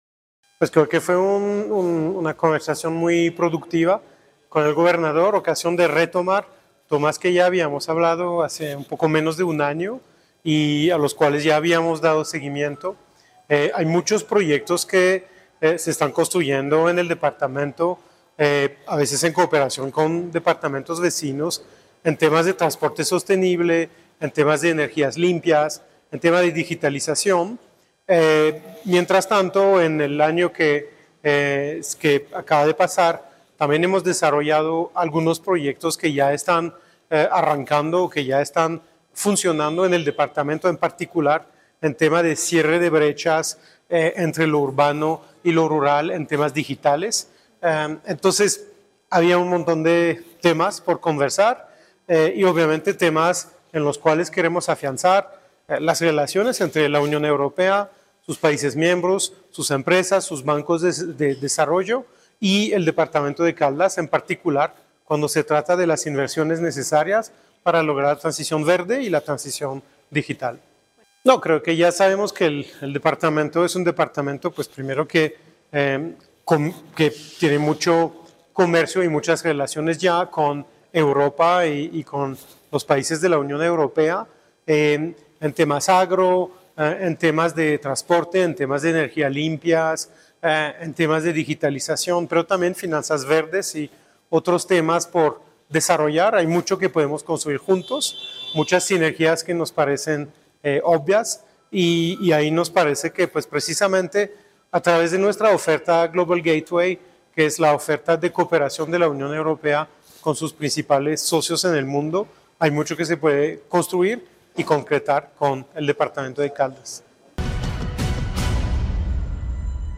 Embajador de la Unión Europea, Gilles Bertrand.